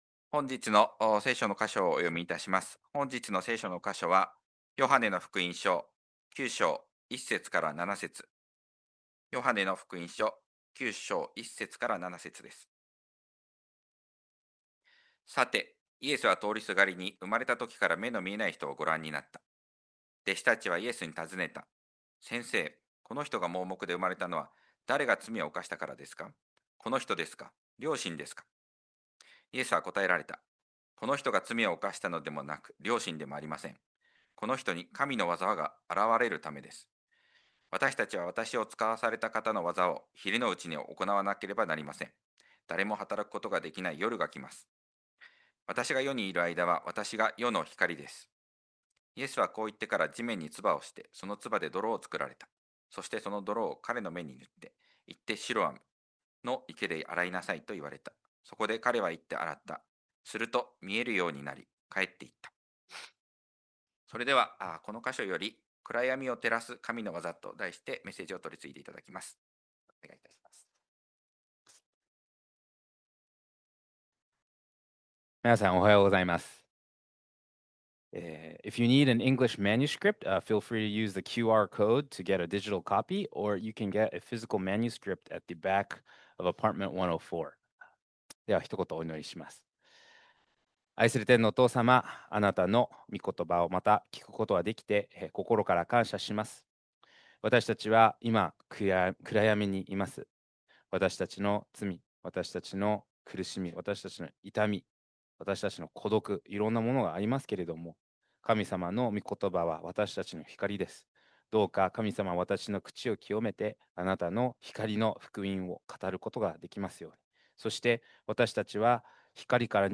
2026年2月15日礼拝 説教 「暗闇を照らす神のわざ」 – 海浜幕張めぐみ教会 – Kaihin Makuhari Grace Church